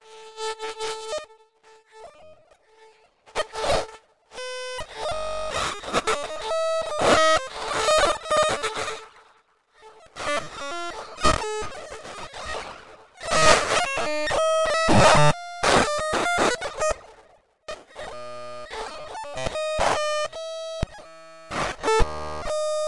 描述：混音的几个样本，可以在这里找到：
Tag: 愤怒 残酷 挤压 滑稽 毛刺 错位 噪声 噪声 配音 非艺术 愤怒 无用 语音